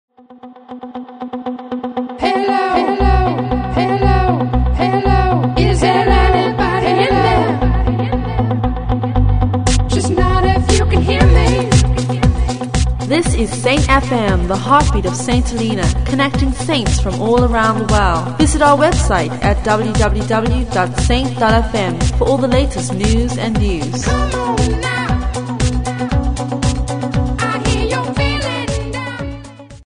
Station trailers
an upbeat trailer for station’s website